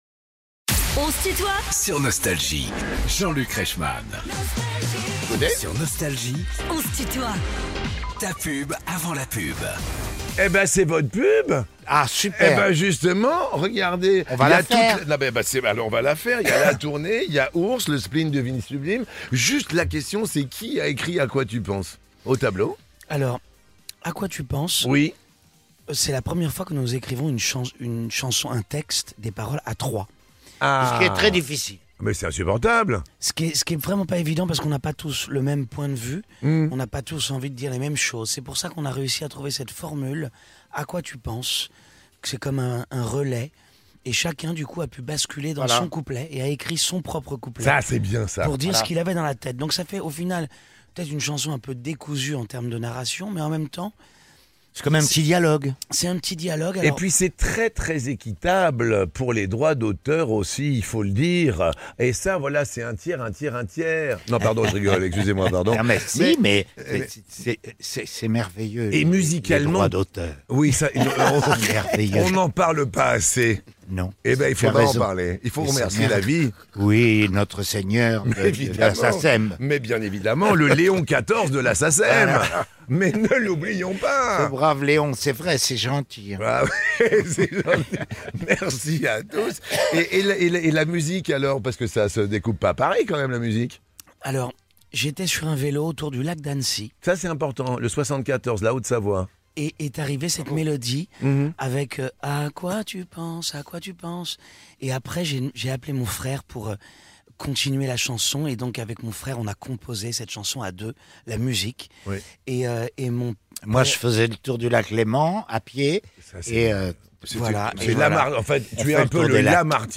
Alain Souchon et ses fils sont les invités de "On se tutoie ?..." avec Jean-Luc Reichmann
Les plus grands artistes sont en interview sur Nostalgie.